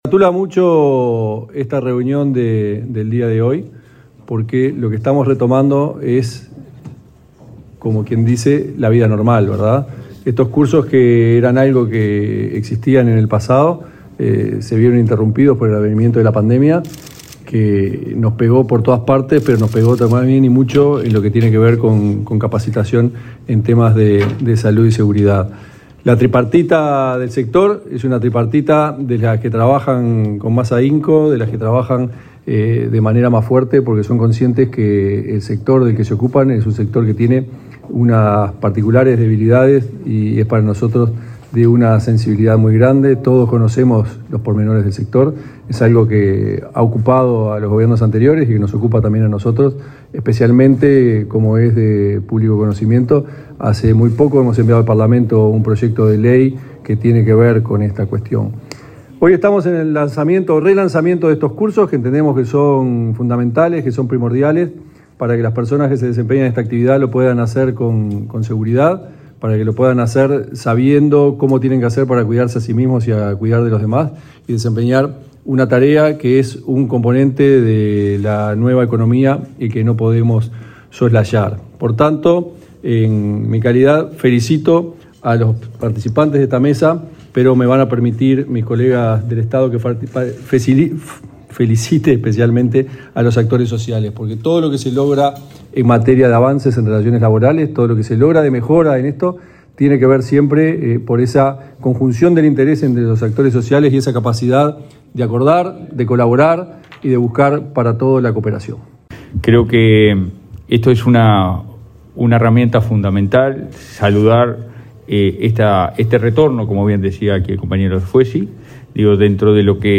Palabras de autoridades en acto del Ministerio de Trabajo
El inspector general de Trabajo, Tomás Teijeiro; el director de la Unidad Nacional de Seguridad Vial (Unasev), Mauricio Viera, y el director general del Instituto Nacional de Empleo y Formación Profesional (Inefop), Pablo Darscht, participaron en el lanzamiento de capacitaciones sobre seguridad vial para repartidores en motocicleta y bicicleta, que se efectúan a través del Inefop. El acto se realizó este lunes 7 en el Ministerio de Trabajo y Seguridad Social (MTSS).